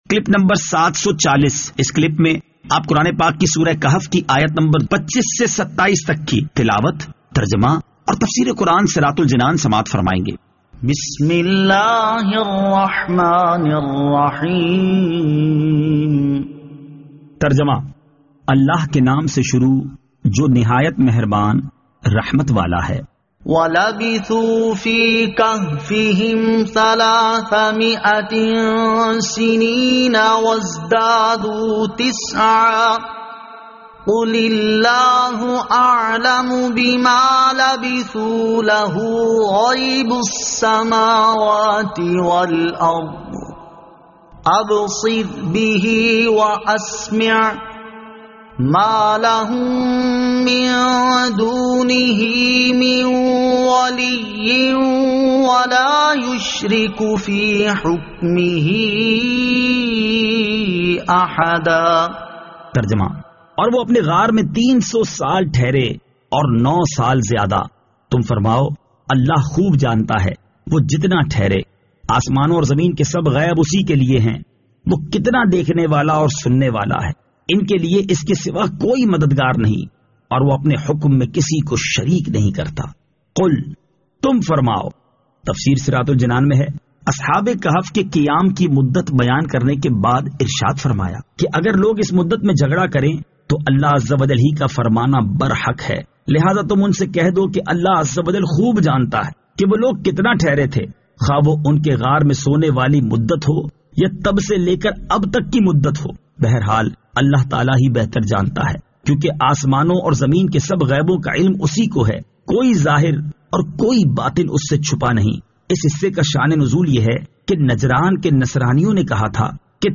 Surah Al-Kahf Ayat 25 To 27 Tilawat , Tarjama , Tafseer